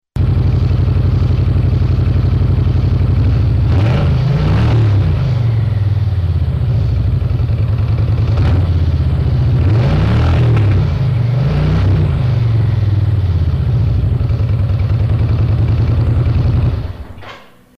Everything was recorded at exactly the same level.
Gutted Stock Nomad Mufflers
vh_shhh_nomad_exhaust_gutted_stock_mufflers.mp3